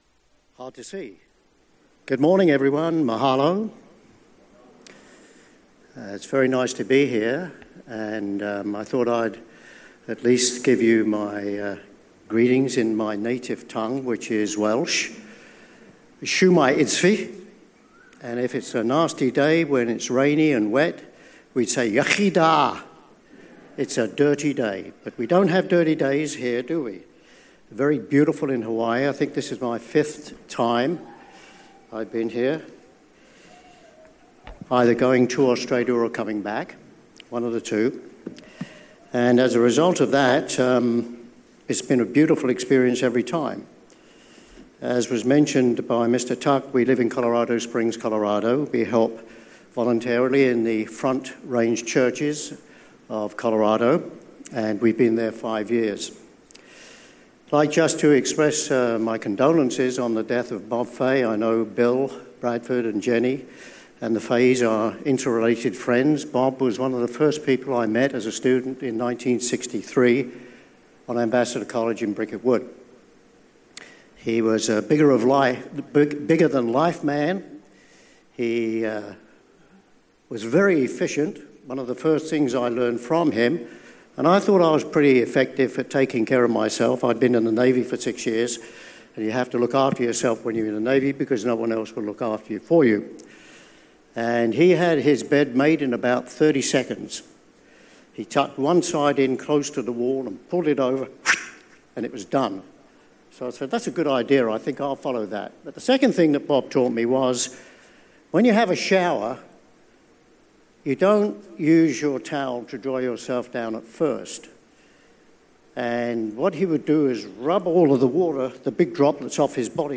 This sermon was given at the Maui, Hawaii 2015 Feast site.